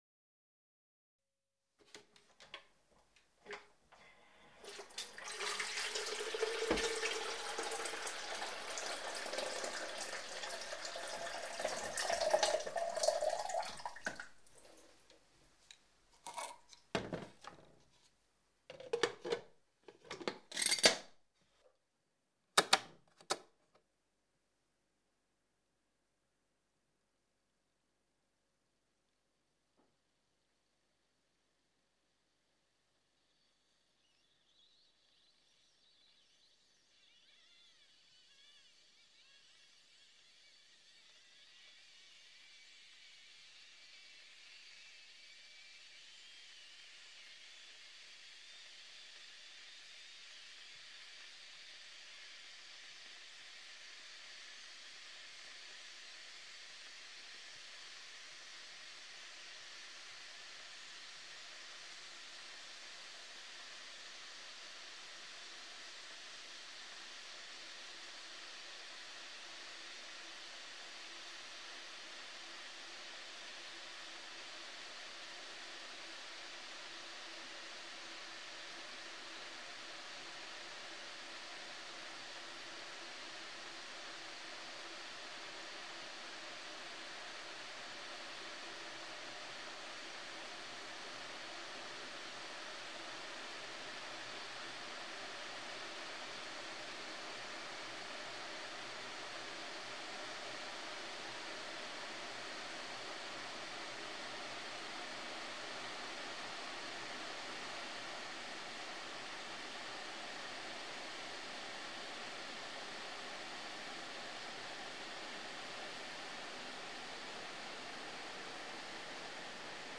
Electric kettle is filled with water, close the lid, turn on, boiling process
• Category: Electric kettle